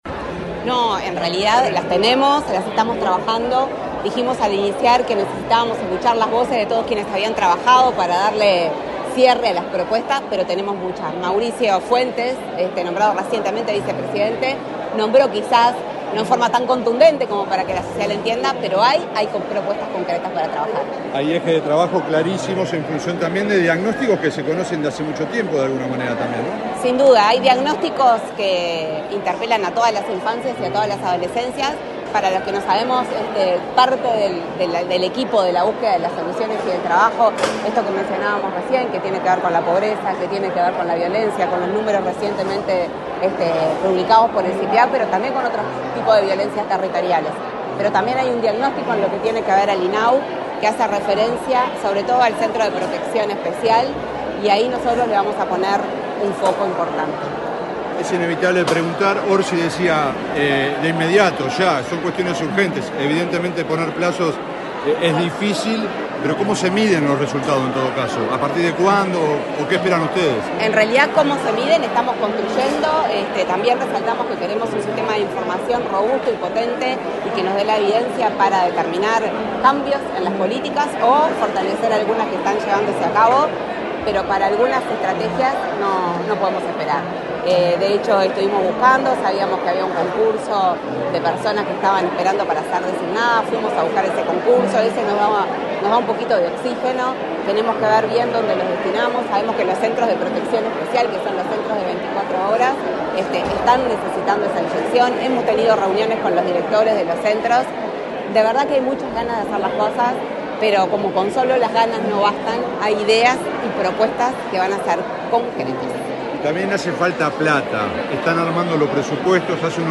Declaraciones de la presidenta del INAU, Claudia Romero
Con la presencia del presidente de la República, profesor Yamandú Orsi, se realizó el acto de asunción de las autoridades del Instituto del Niño y